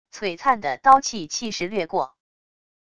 璀璨的刀气气势掠过wav音频